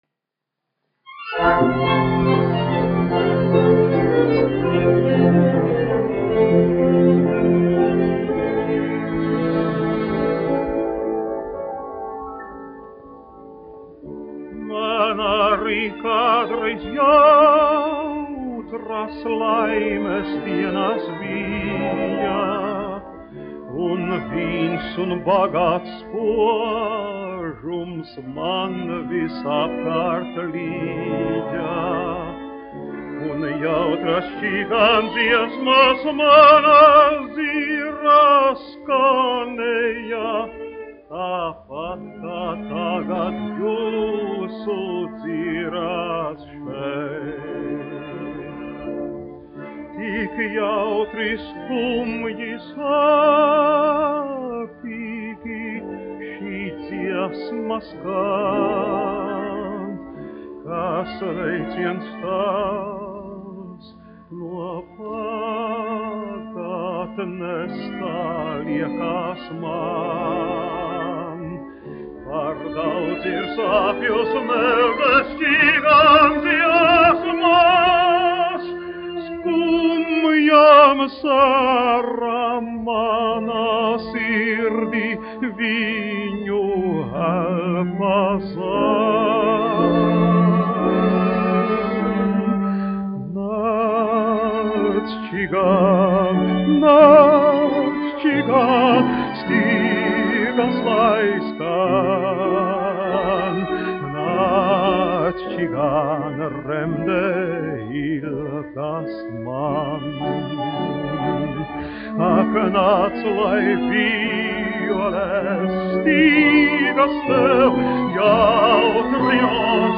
1 skpl. : analogs, 78 apgr/min, mono ; 25 cm
Operetes--Fragmenti
Skaņuplate